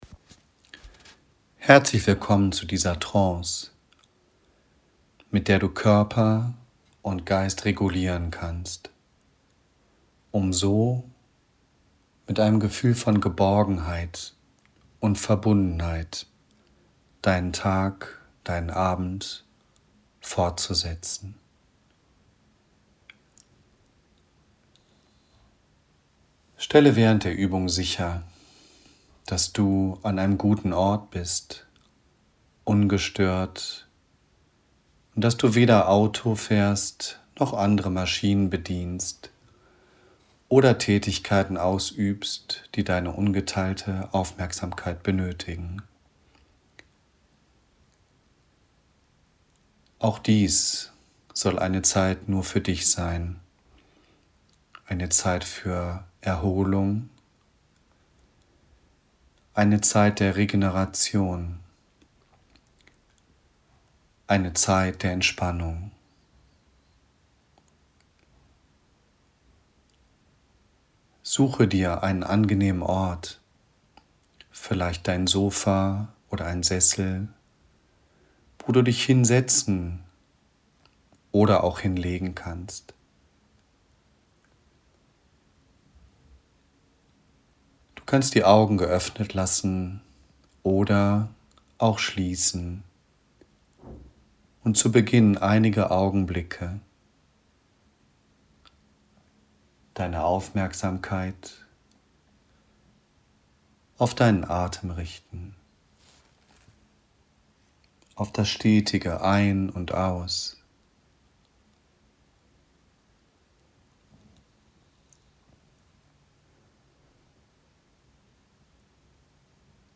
Hier können Sie sich die zu Karte 4.9 zugehörige Trance herunterladen.